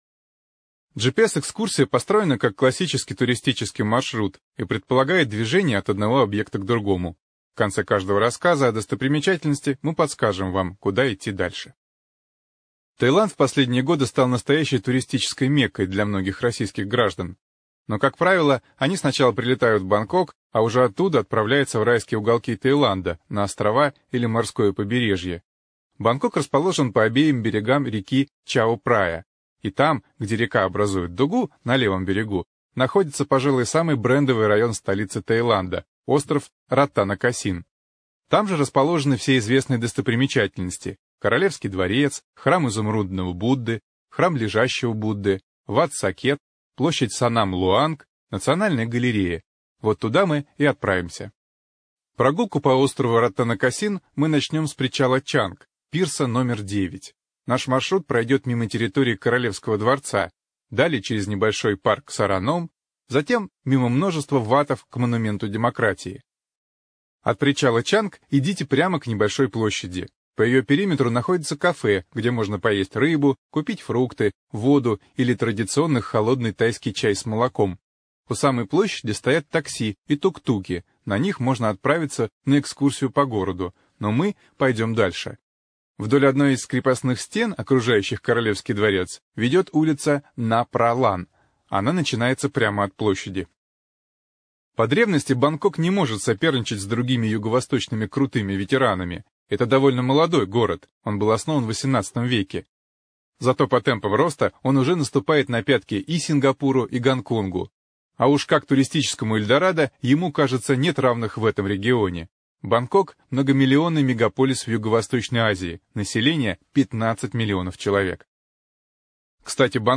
ЖанрАудиоэкскурсии и краеведение